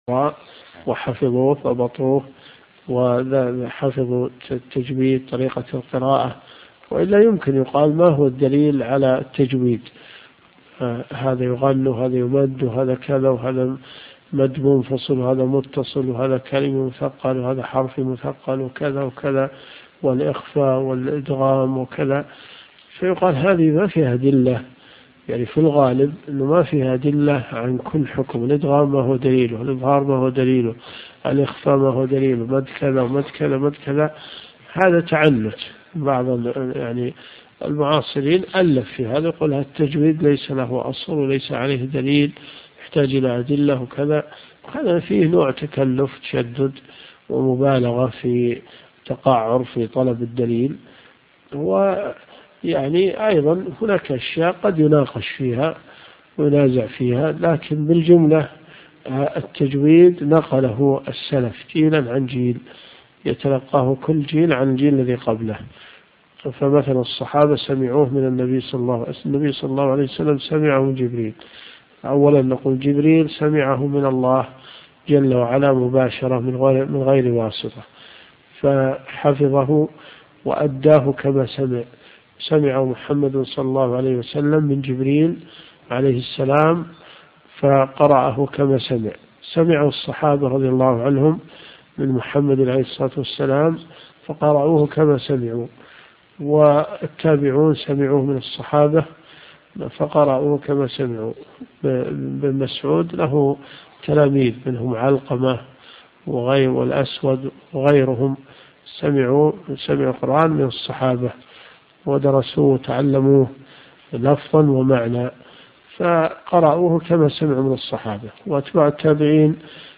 الرئيسية الكتب المسموعة [ قسم الحديث ] > صحيح البخاري .